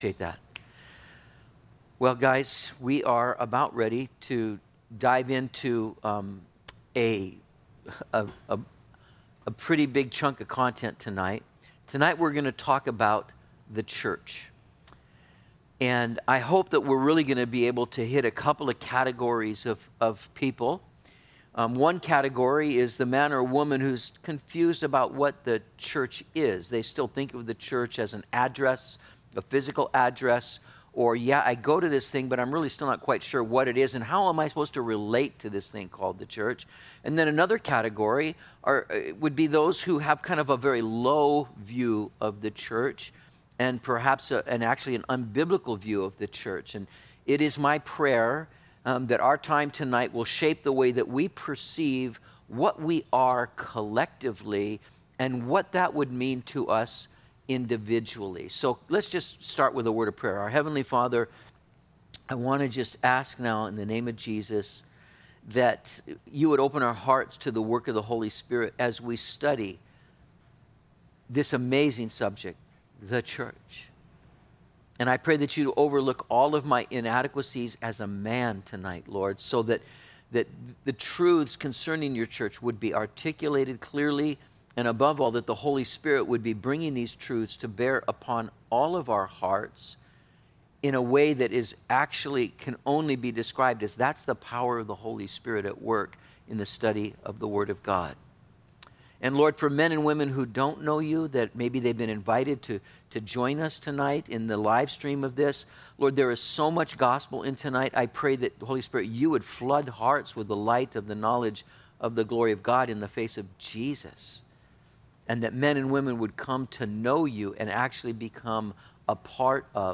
08/17/20 The Church - Metro Calvary Sermons